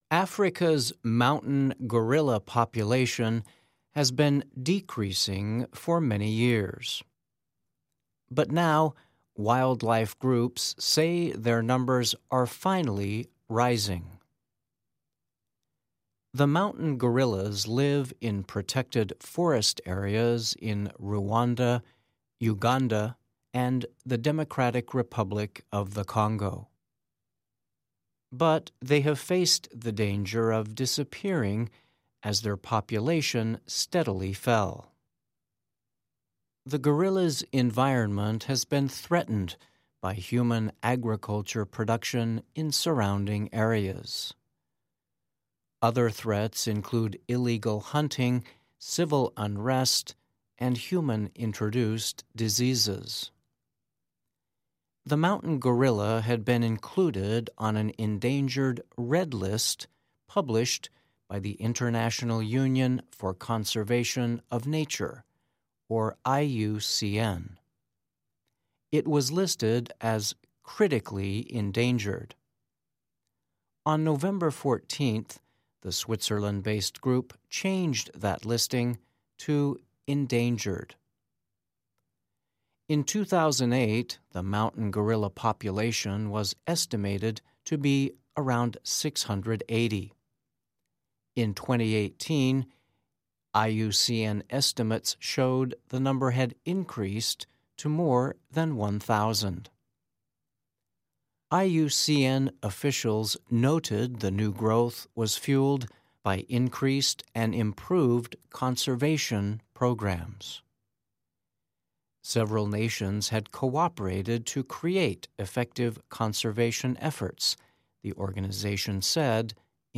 慢速英语:保护努力帮助山地猩猩种群增长